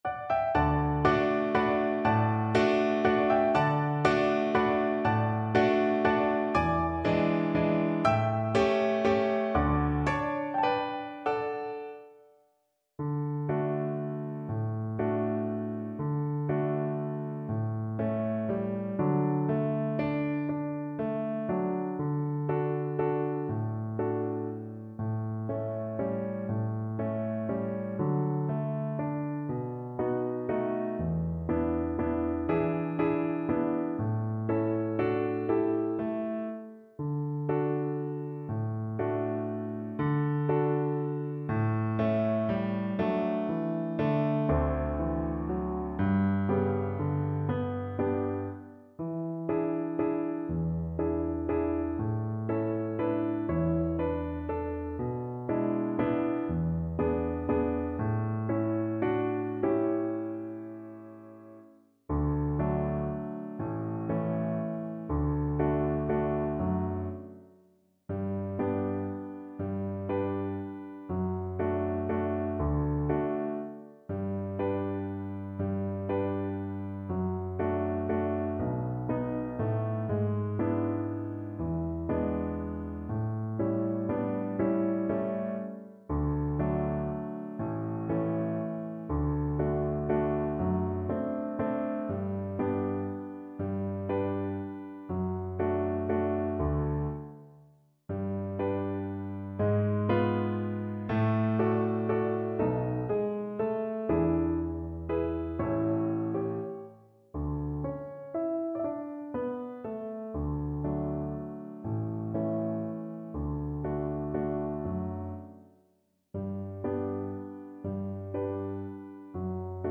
3/4 (View more 3/4 Music)
Valse moderato espressivo = 120
Pop (View more Pop Cello Music)